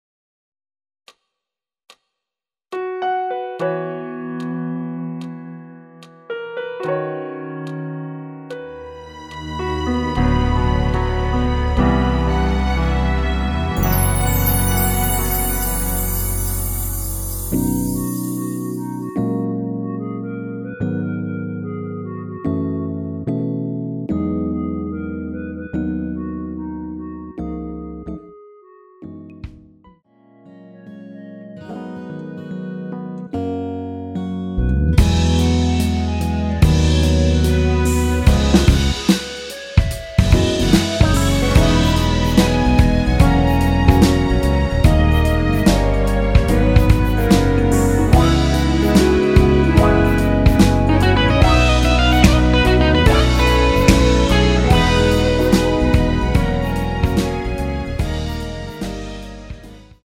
원키에서(-6)내린 멜로디 포함된 MR입니다.
멜로디 MR이라고 합니다.
앞부분30초, 뒷부분30초씩 편집해서 올려 드리고 있습니다.
중간에 음이 끈어지고 다시 나오는 이유는